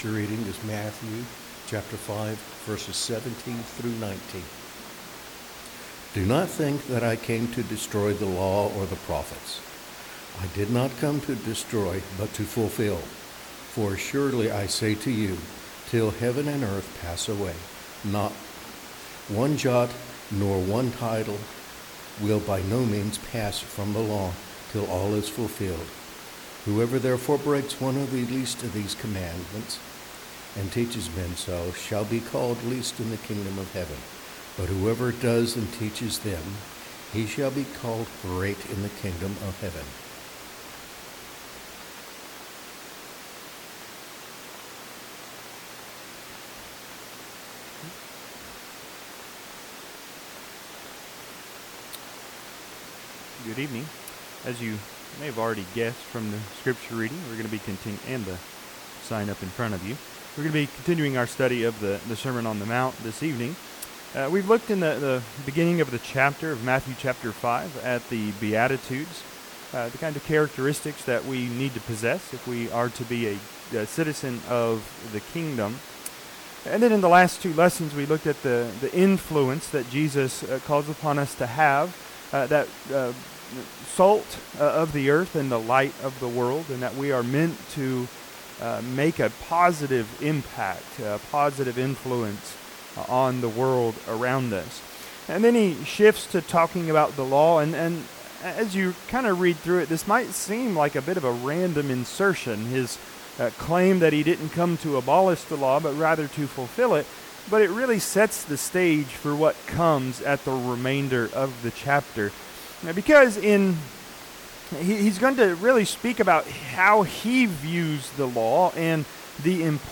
Matthew 5:17-19 Service Type: Sunday PM How exactly did the Lord Jesus fulfill the Law?